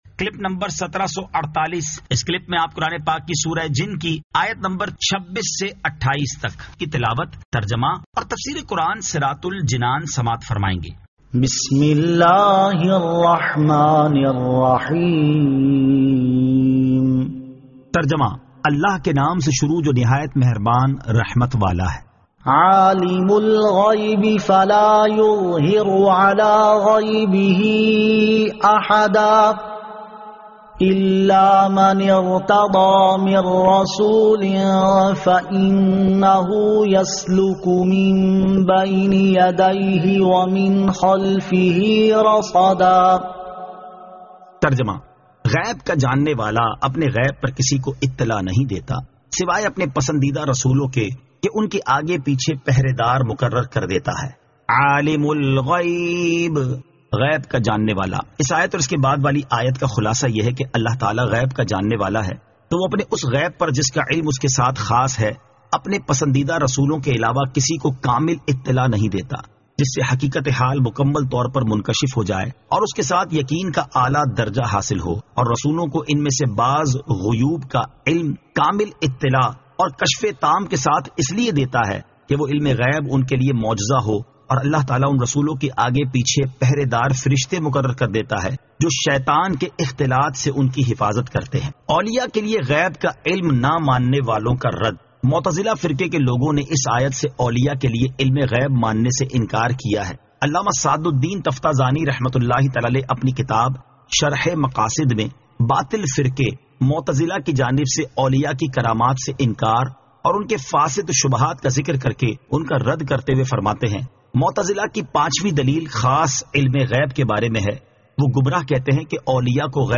Surah Al-Jinn 26 To 28 Tilawat , Tarjama , Tafseer